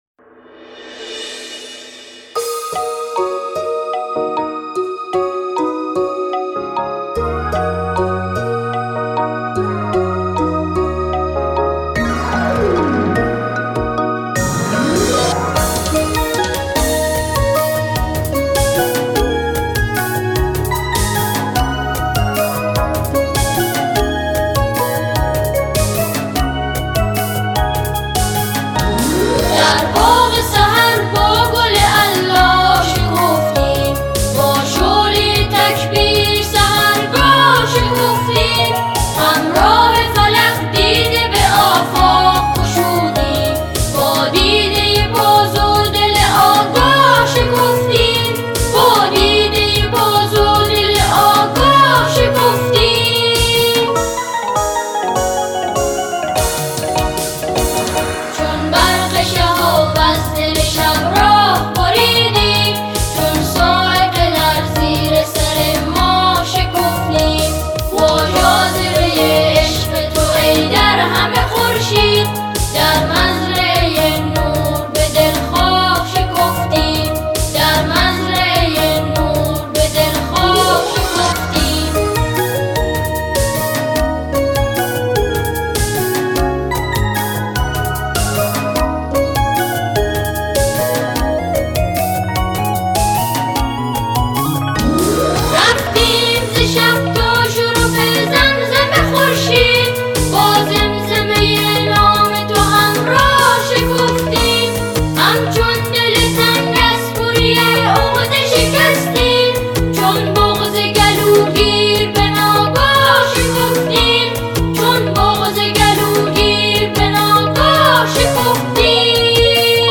سرود پیشرفت